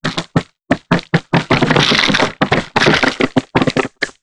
ALIEN_Communication_31_mono.wav